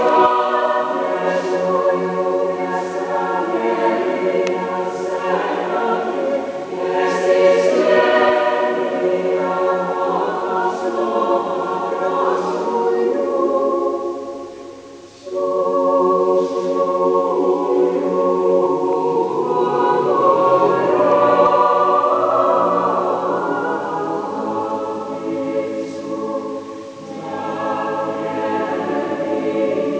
Слике, аудио и видео записи са концерта 5 јуна, 2006 г.